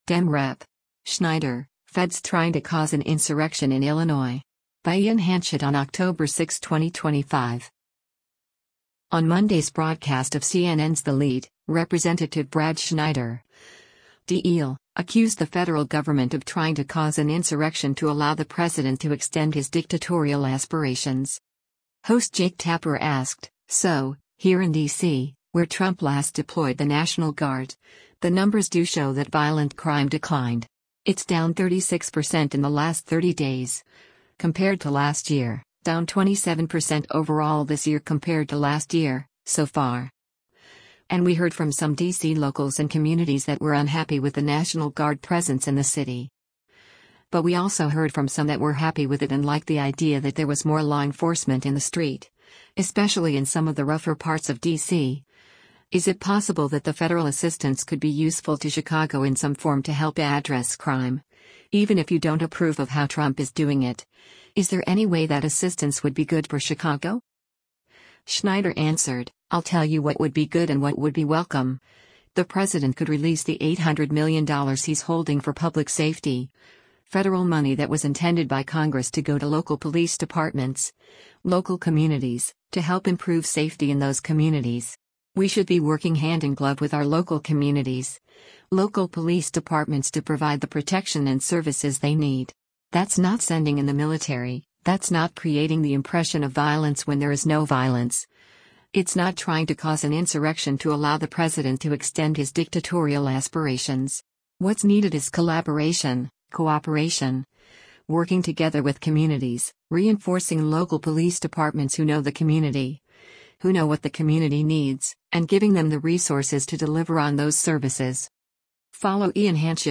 On Monday’s broadcast of CNN’s “The Lead,” Rep. Brad Schneider (D-IL) accused the federal government of “trying to cause an insurrection to allow the president to extend his dictatorial aspirations.”